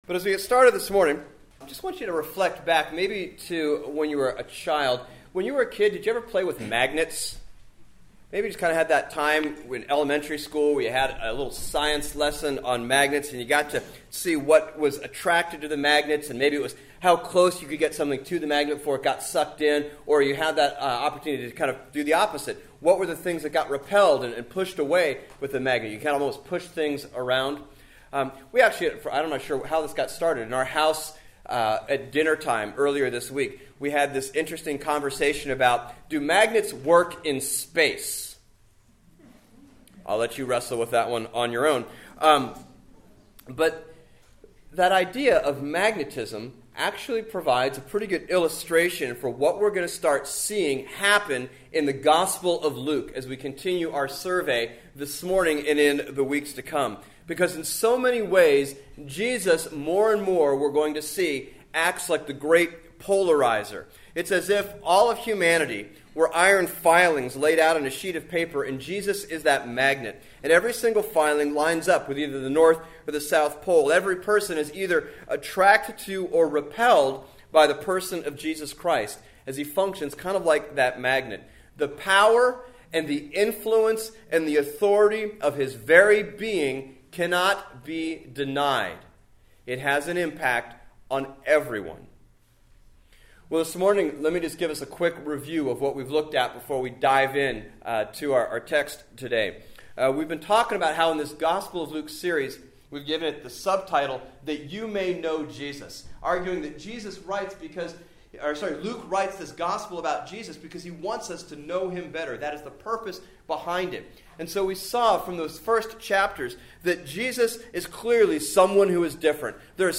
Bible Text: Luke 5:1 - 6:11 | Preacher: